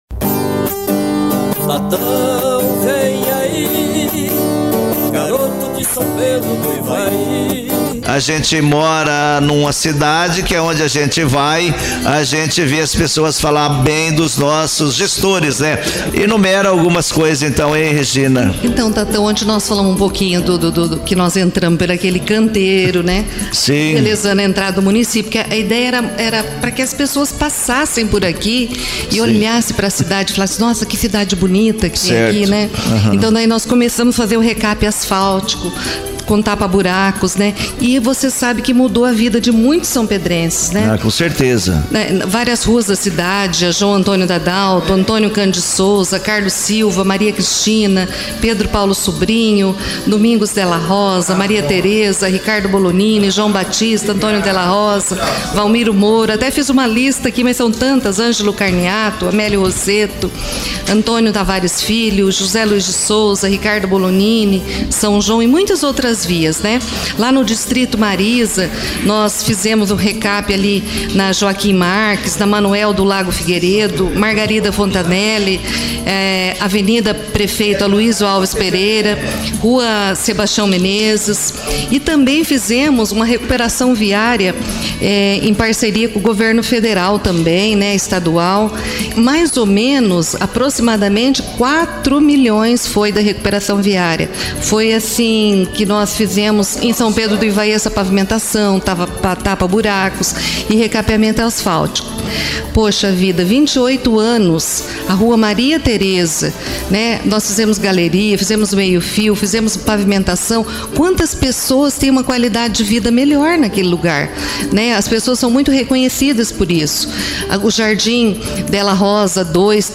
Maria Regina participa de programa de rádio na despedida da prefeitura